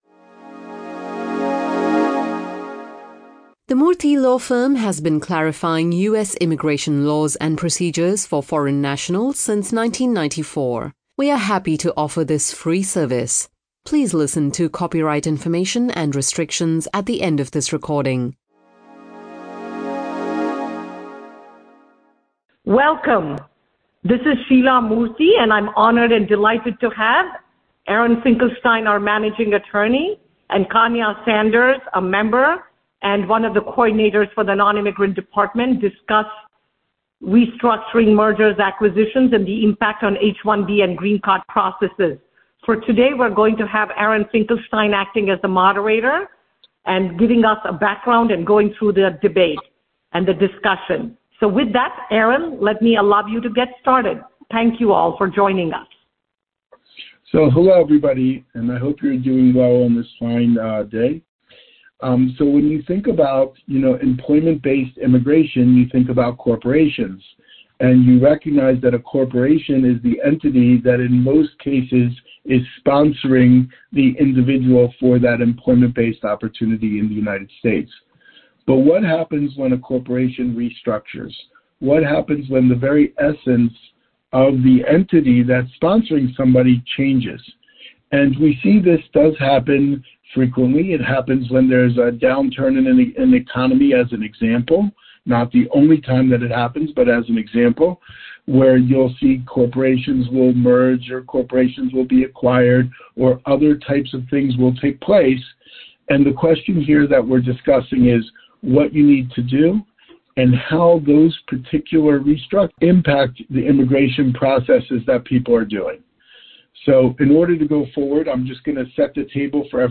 The effects of corporate restructuring on immigration petitions are discussed here by Murthy Law Firm attorneys in this podcast recorded for Employers. Restructuring and its effects on H1B petitions and employment-based green cards (at the labor certification stage, during the I-140 petition, and after the I-140 petition) are discussed.